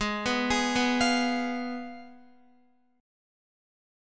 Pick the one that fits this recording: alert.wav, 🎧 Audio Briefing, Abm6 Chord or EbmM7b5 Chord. Abm6 Chord